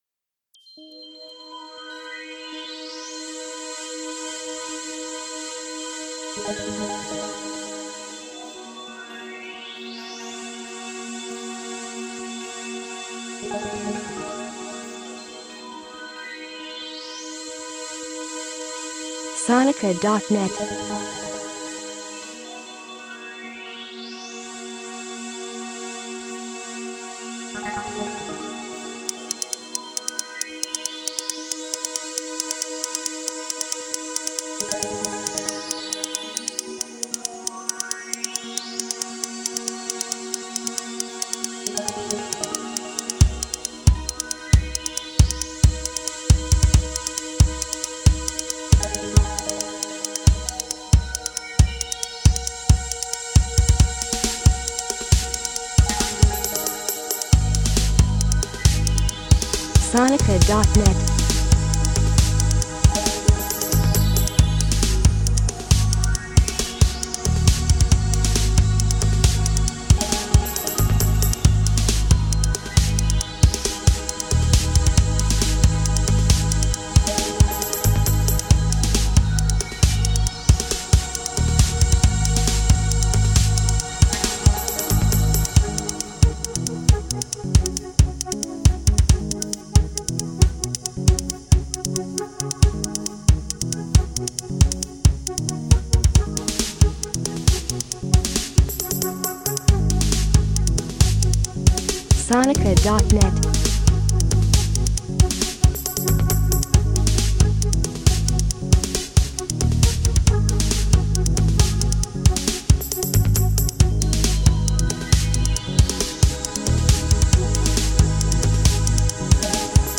A sonic journey through emotional landscapes.